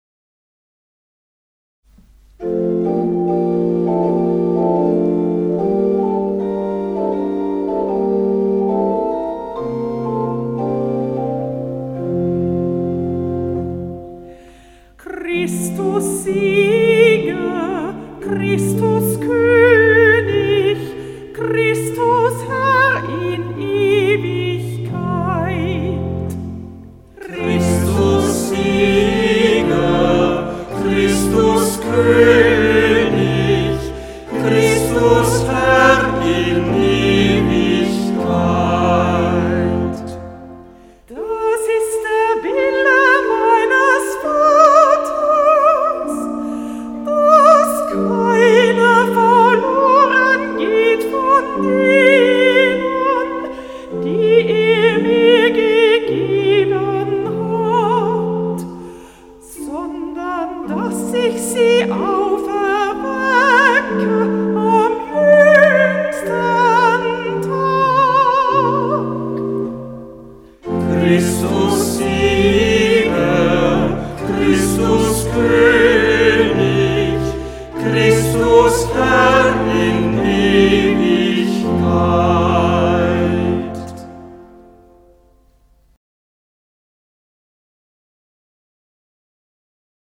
Ruf vor dem Evangelium - November 2025
Hörbeispiele aus dem Halleluja-Büchlein